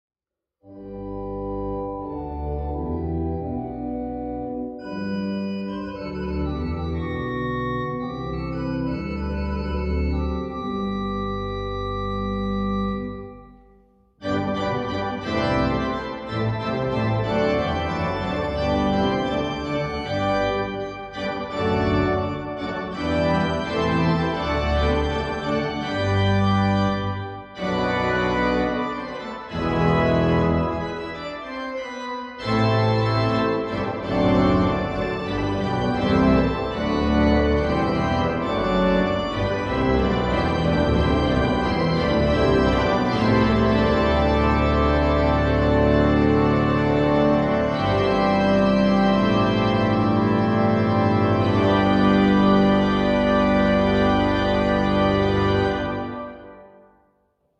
Opgesteld met 10 kanalen audio, zonder kunstmatig toegevoegde nagalm.
Na de intonatie op locatie, spelen we het orgel natuurlijk ook zelf even door.